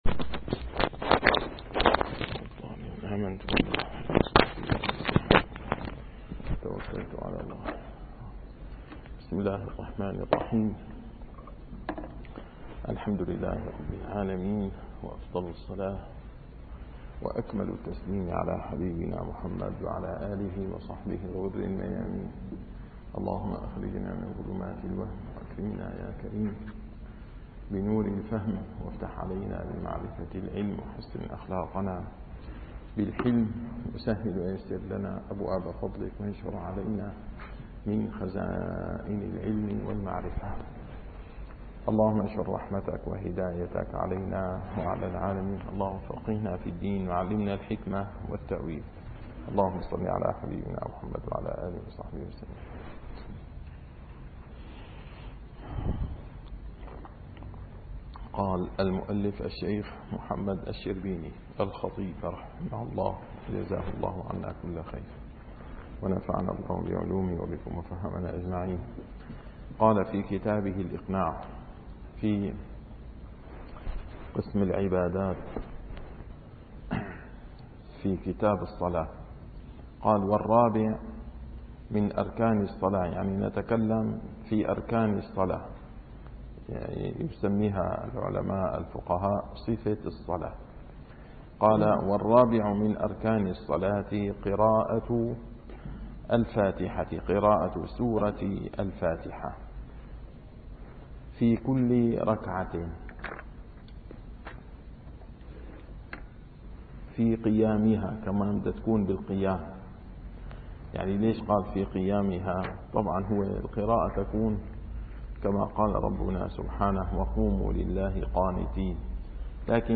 - الدروس العلمية - الفقه الشافعي - كتاب الإقناع - تتمة أركان الصلاة (قراءة الفاتحة)